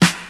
• Old School Natural Snare Drum Sample F Key 55.wav
Royality free snare sample tuned to the F note. Loudest frequency: 2054Hz
old-school-natural-snare-drum-sample-f-key-55-YKj.wav